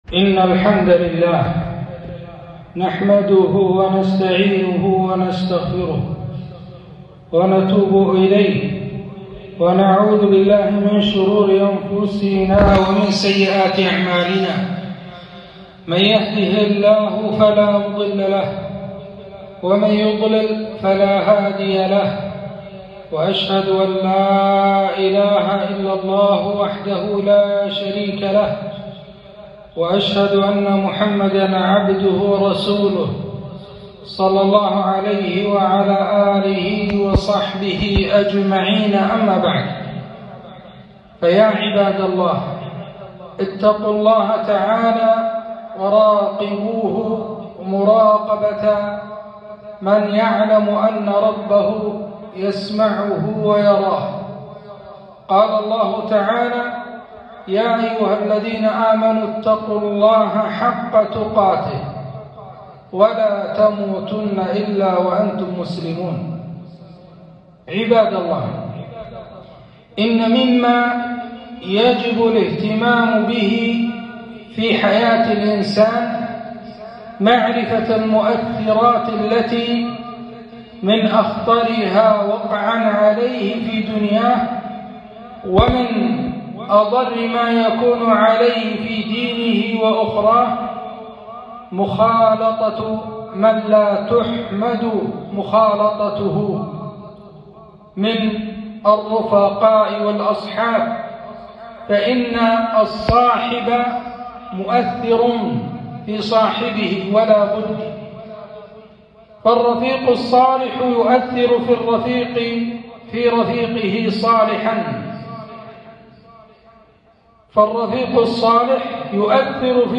خطبة - الجليس الصالح والجليس السوء